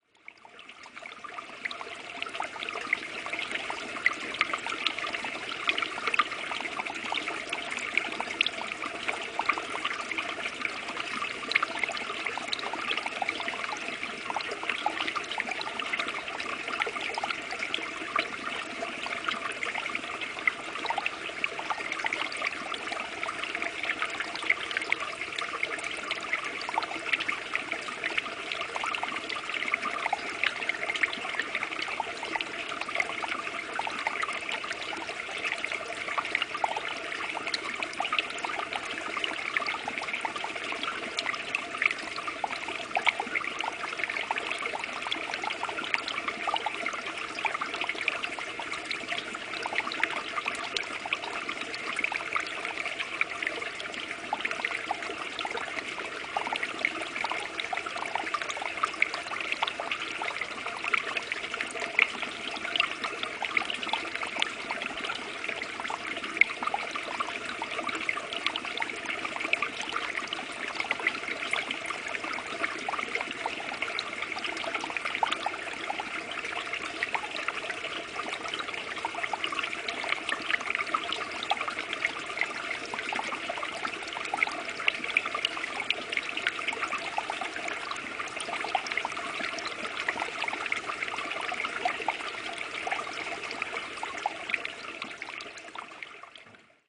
Ce tunnel est à présent utilisé par les automobiles.
704train.mp3